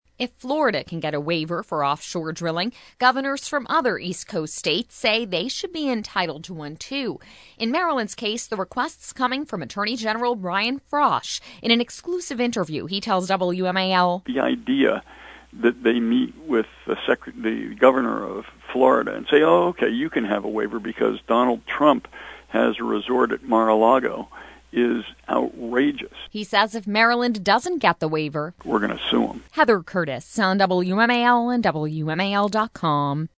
WASHINGTON (WMAL) – Maryland is among the East Coast states that want what Florida got – an offshore drilling waiver. Maryland’s Attorney General Brian Frosh told WMAL in an exclusive interview the state will petition for one.